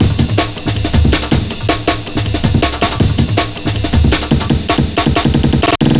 Index of /90_sSampleCDs/Zero-G - Total Drum Bass/Drumloops - 1/track 05 (160bpm)